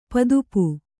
♪ padupu